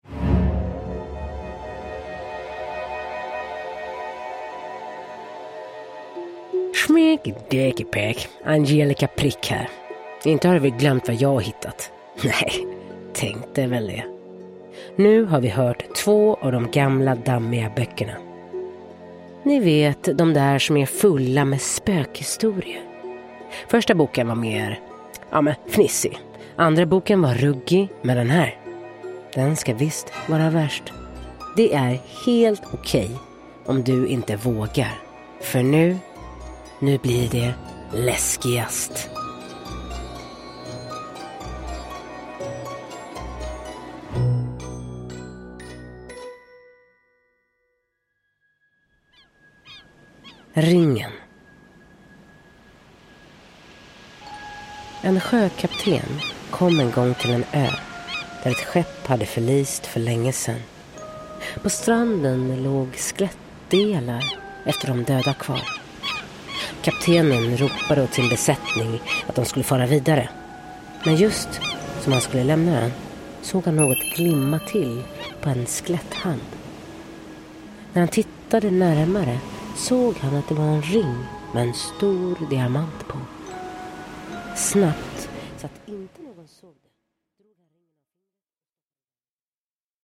Spökhistorier : nu blir det läskigast! – Ljudbok
Med kuslig röst